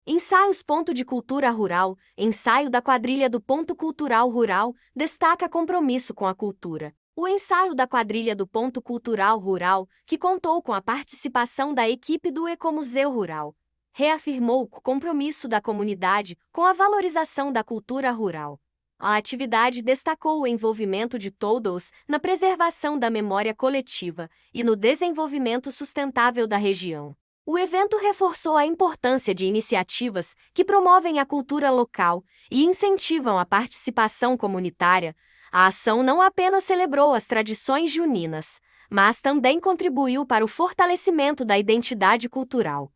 Ensaio da Quadrilha do Ponto Cultural Rural destaca compromisso com a cultura